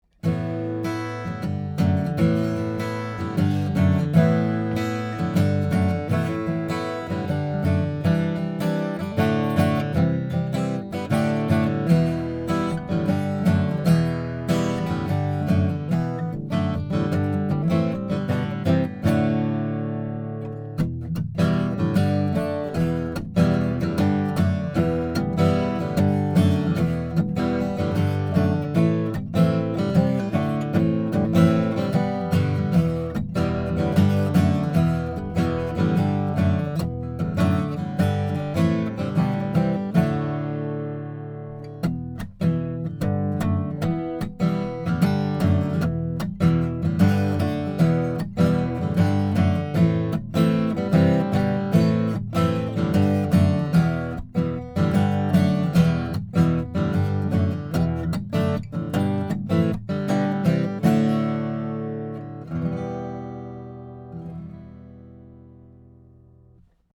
in Em